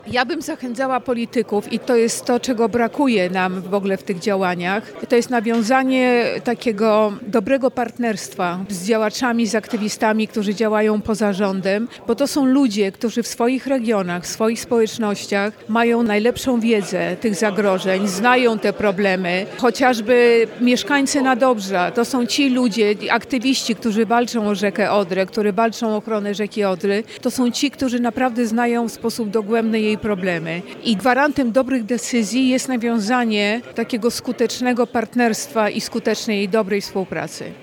Gościem specjalnym konferencji była Ewa Ewart – uznana dziennikarka, reżyserka i producentka. W rozmowie dla Twojego Radia podkreśliła, jak ważne jest, aby władze centralne wsłuchiwały się w głos lokalnych społeczności. Zwróciła uwagę na potrzebę budowania dialogu i współpracy na rzecz środowiska.